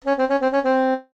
jingles-saxophone_02.ogg